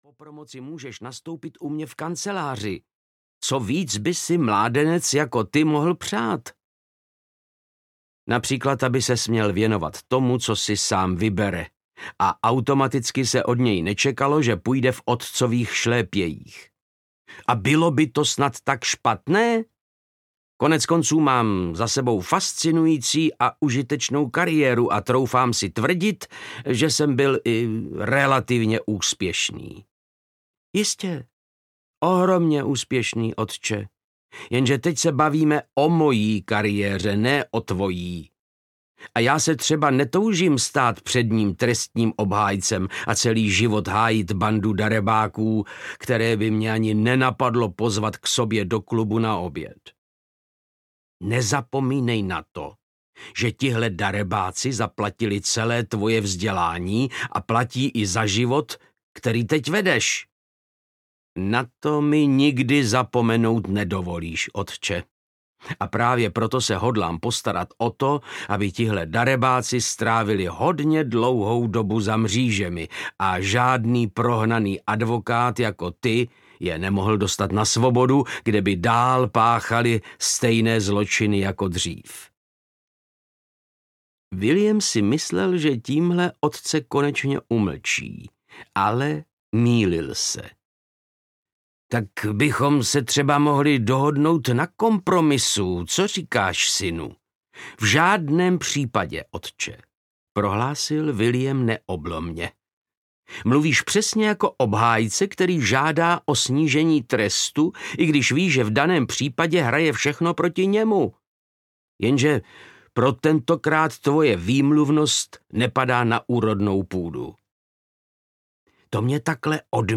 Kdo neriskuje... audiokniha
Ukázka z knihy
• InterpretVáclav Knop